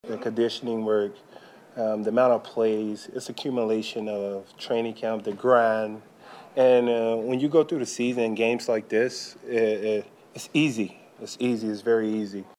5. Chiefs DT Chris Jones says the early season success is attributed to the team’s tough training camp